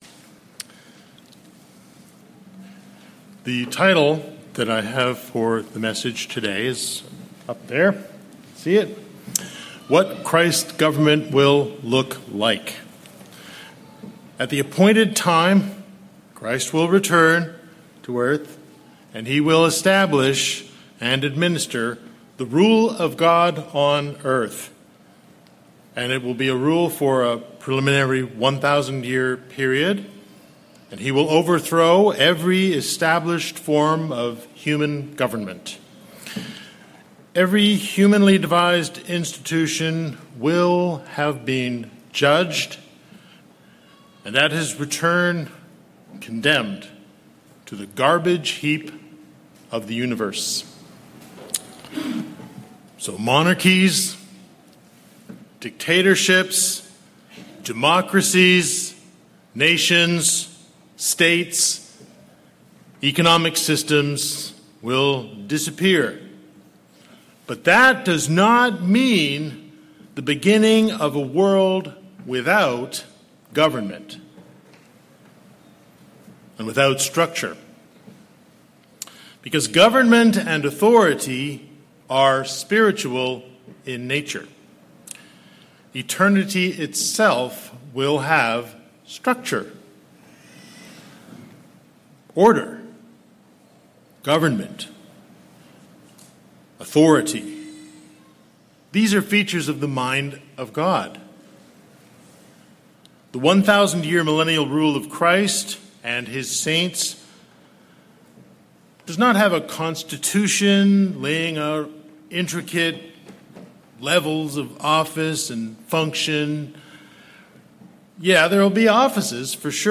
Sermons
Given in Morehead City, North Carolina